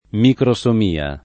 microsomia [ mikro S om & a ]